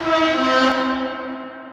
deltic_honk_2.ogg